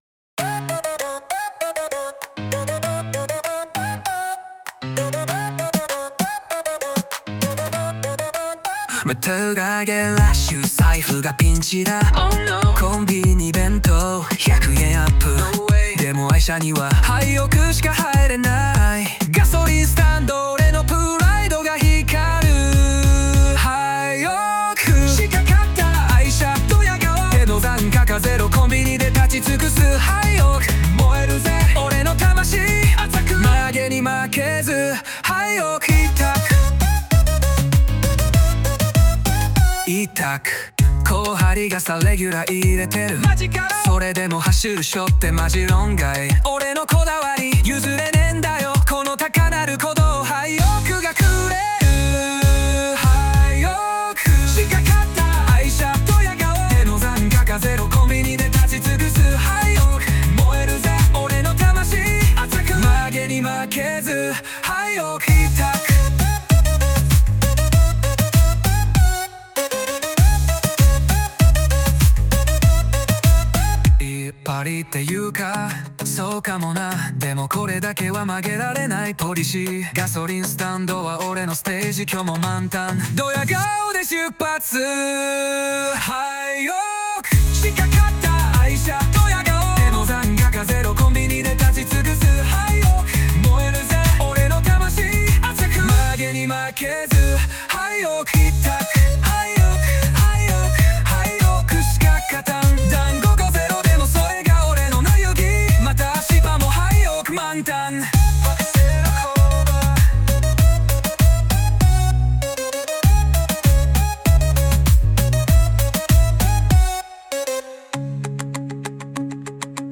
Sunoで実際に生成された楽曲「ハイオクしか勝たん！」
今回はヒップホップ調で、ヤンキーのテンションを表現するビートを目指しました。
ソングスタイル: J-Pop, Hip-Hop, Energetic, Male Vocal Rap, Humorous, Catchy
Sunoに「アップテンポなヒップホップ、若者に響くビート、ヤンキー感のある男声ラップ」を英訳して「J-Pop, Hip-Hop, Energetic, Male Vocal Rap, Humorous, Catchy」と入力して生成をお願いしました。
軽快なビートに、ちょっとダサいけど憎めないラップが乗っていて、ガソリンスタンドでドヤるヤンキーの雰囲気がバッチリ再現されてました。